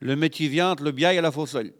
Localisation Saint-Jean-de-Monts
Catégorie Locution